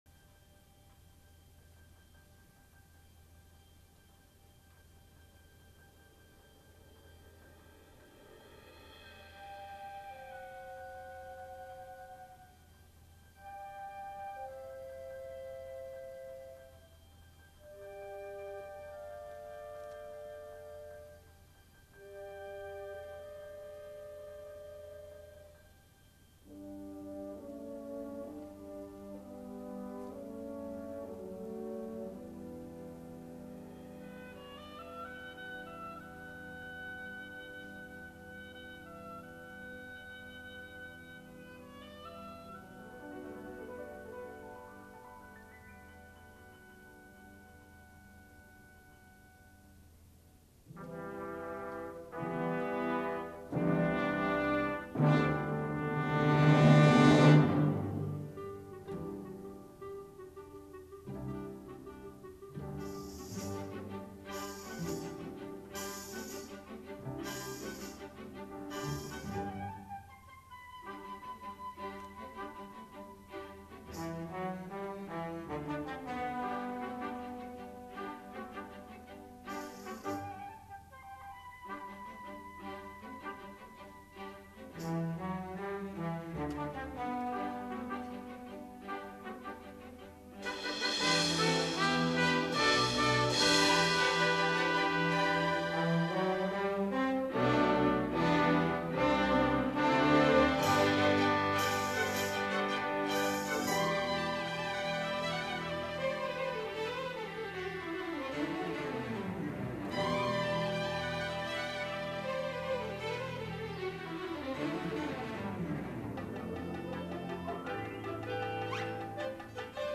for Orchestra (1999)